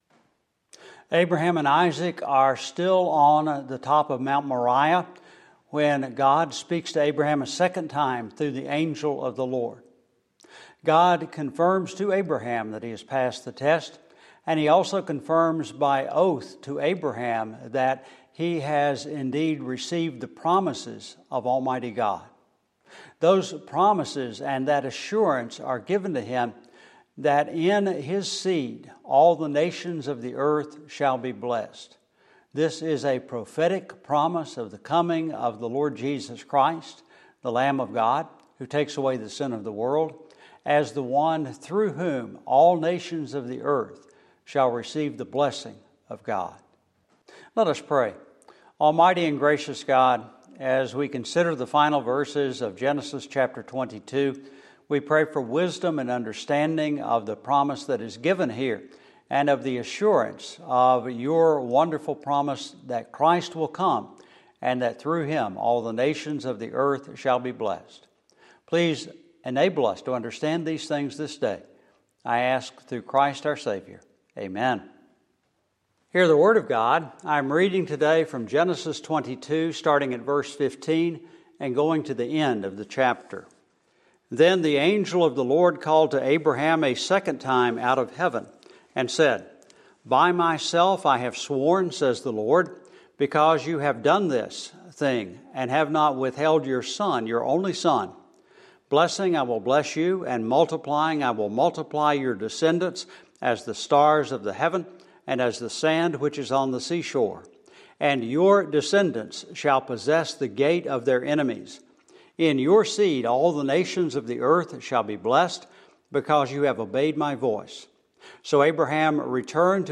Our current sermon series is on 1, 2, and 3 John